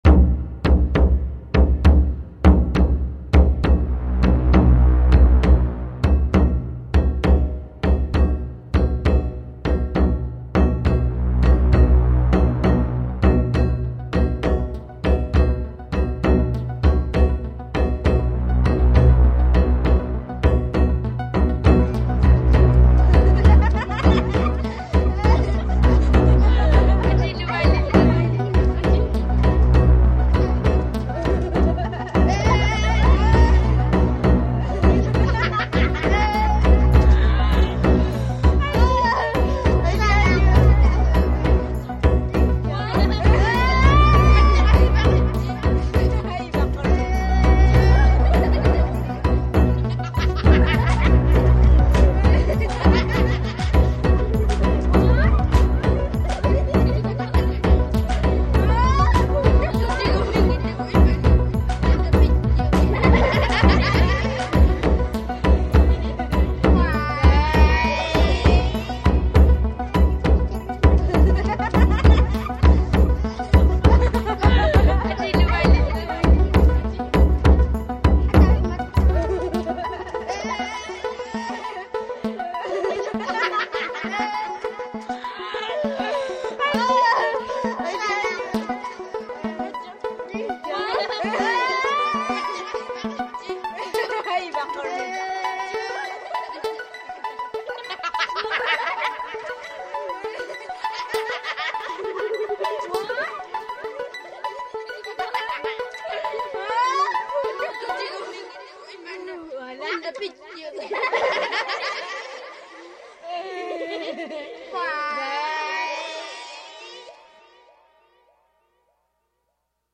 1. Environmental sounds related to the historic period
3. Composed musical sequences.